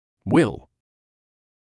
[wɪl][уил]вспомогательный глагол для образования будущего времени